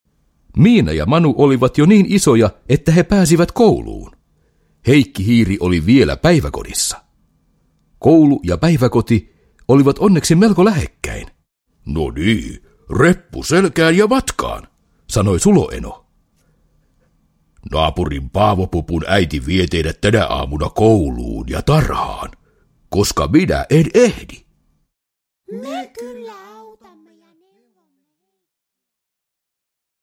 Miina ja Manu koulutiellä – Ljudbok – Laddas ner